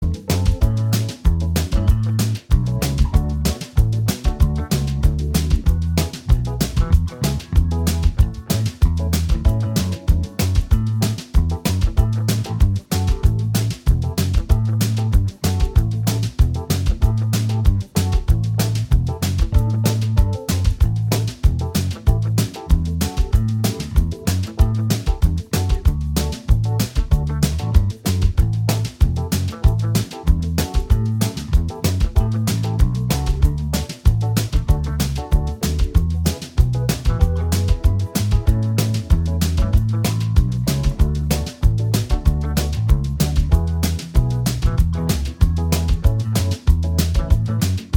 Minus Main Guitar Soft Rock 3:48 Buy £1.50